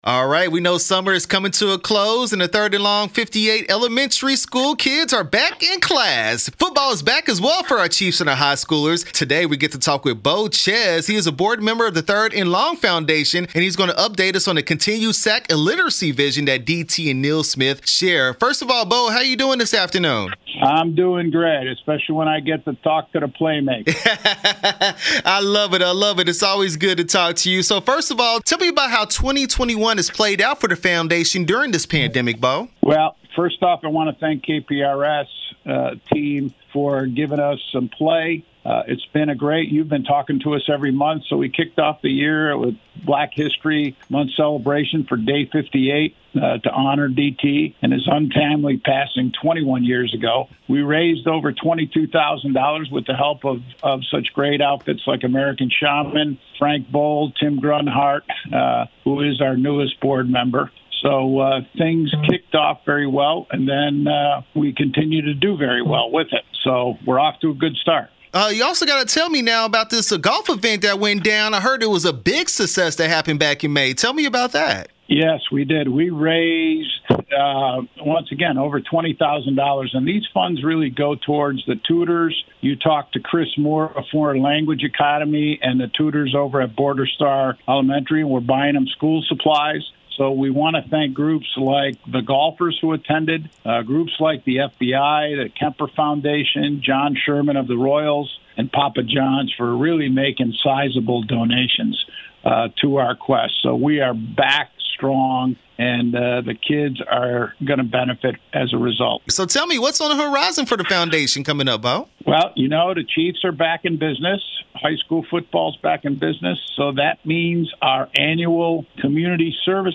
3 Hot Minutes-Third And Long Foundation interview 9/16/21